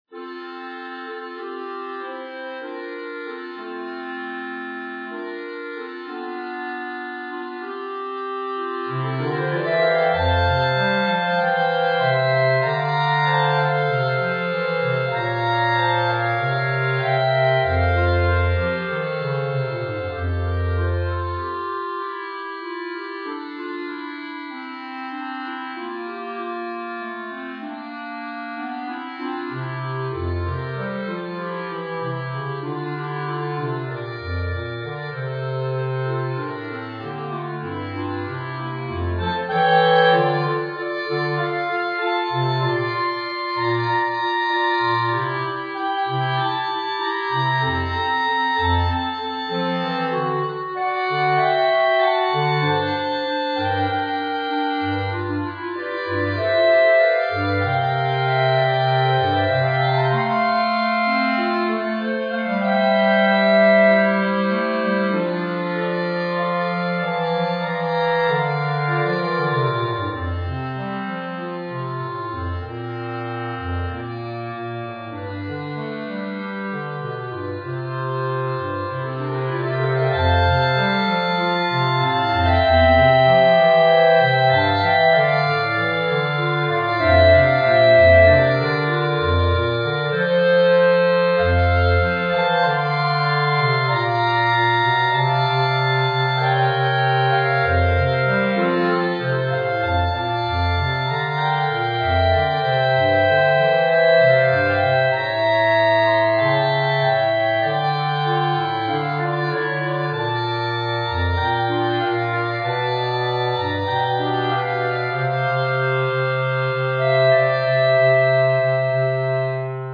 B♭ Clarinet 1 B♭ Clarinet 2 B♭ Clarinet 3 Bass Clarinet
单簧管四重奏
风格： 流行